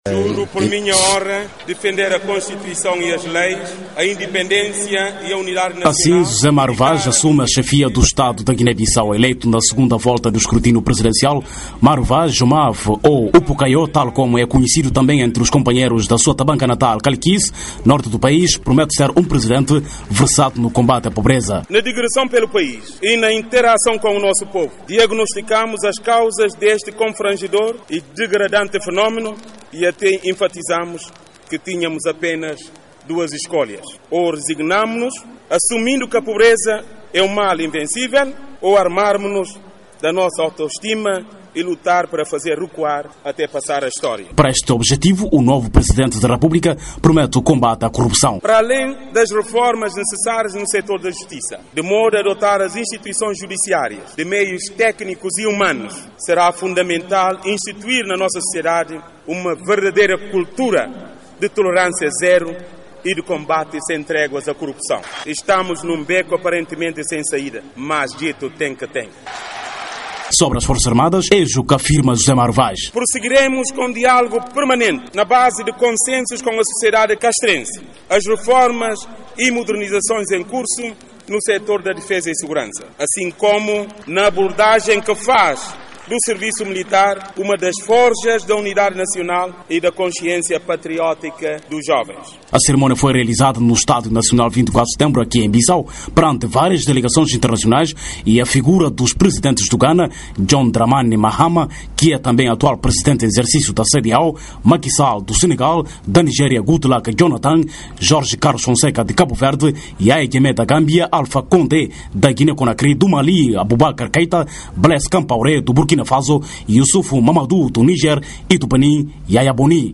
A cerimónia foi realizada no Estádio Nacional 24 de Setembro, em Bissau, perante várias delegações internacionais.